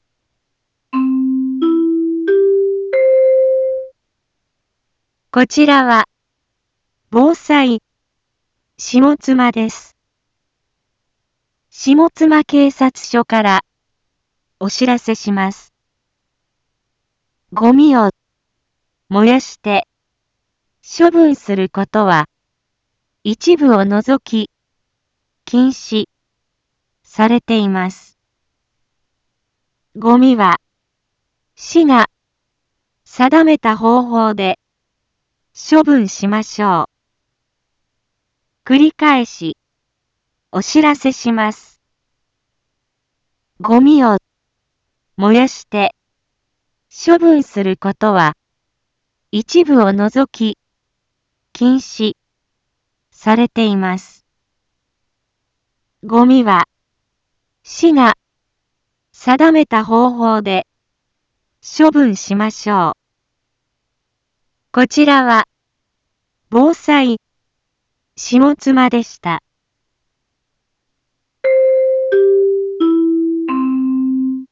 一般放送情報
Back Home 一般放送情報 音声放送 再生 一般放送情報 登録日時：2025-10-24 10:01:35 タイトル：ごみの野焼き禁止（啓発放送） インフォメーション：こちらは、ぼうさいしもつまです。